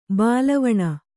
♪ bālavaṇa